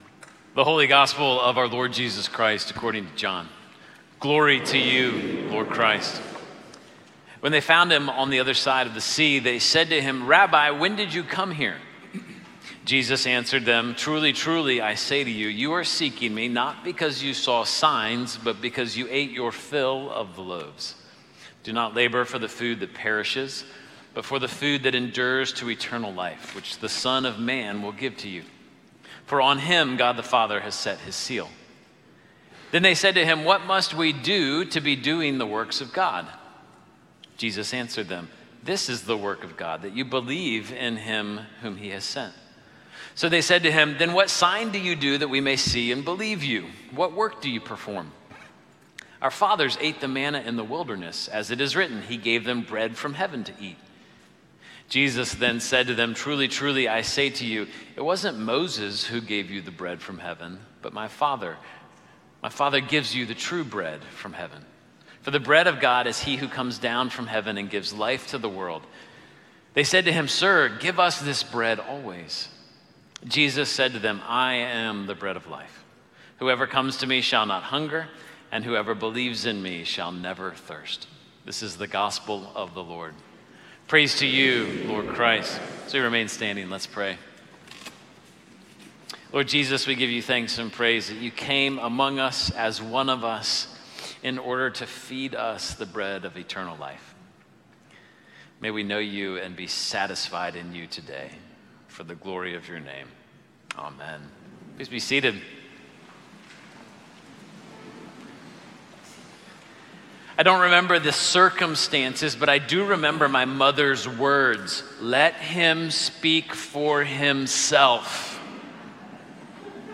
Sermons - Holy Trinity Anglican Church